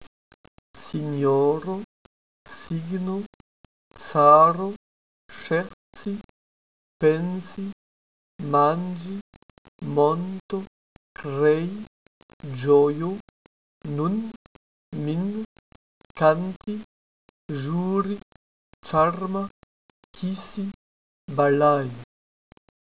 EXEMPLES DE PRONONCIATON
Ainsi les mots français : caille, oseille, rouille, s'écriraient en Espéranto : kaj, ozej, ruj.
De même : pavane, Chine, trône, s'écriraient en Espéranto : pavan, ŝin, tron.
mots.wav